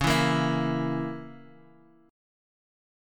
Dbm6 chord